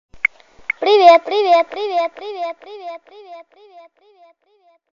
Аудио привет детским голосом
• Категория: Привет(приветствие)
• Качество: Высокое
На этой странице вы можете прослушать звук аудио привет детским голосом.